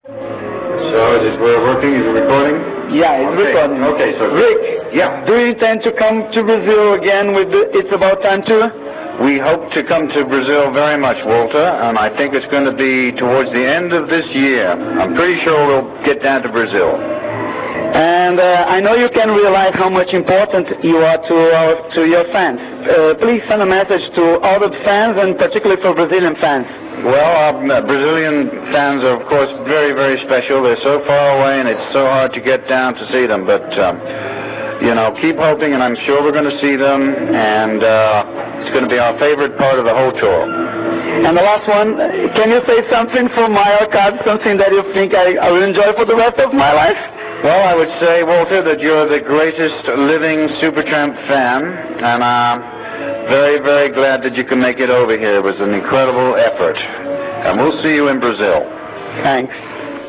An exclusive interview with Rick Davies !!! Backstage in Austria (Imst, May, 1997)